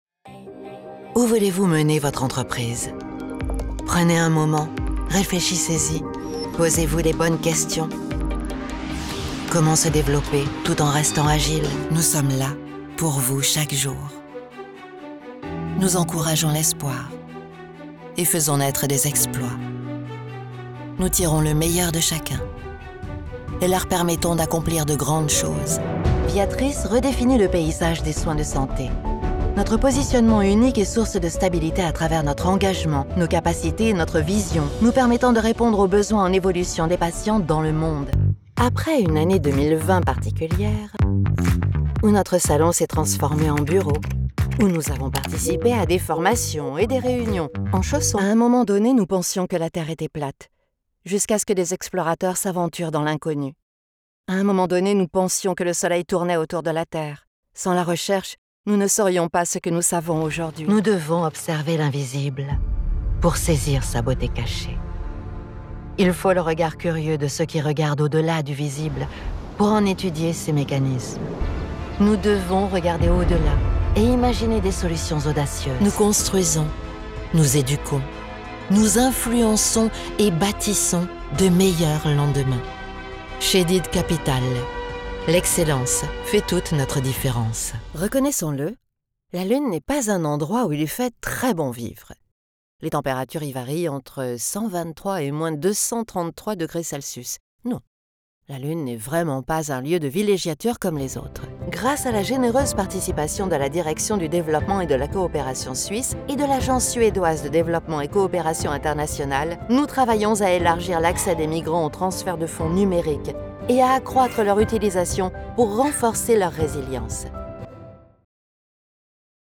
Female
French (Parisienne)
Adult (30-50)
Corporate Generic Demo